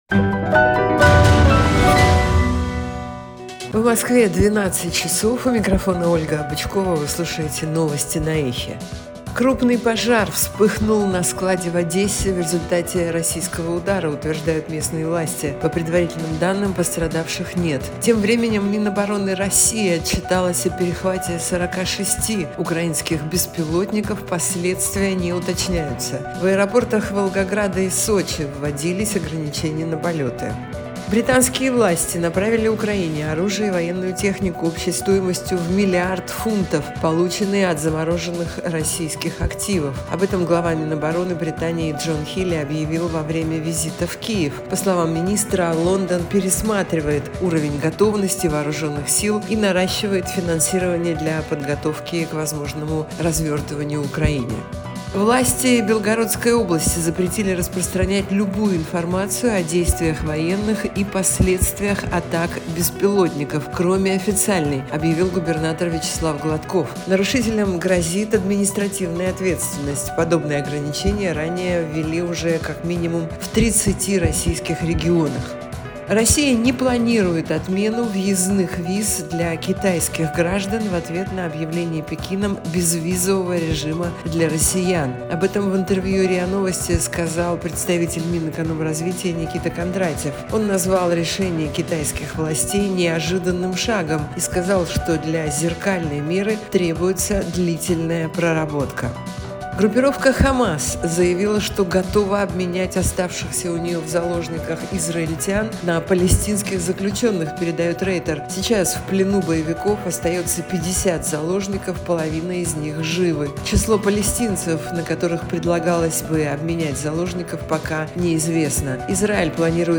Новости 12:00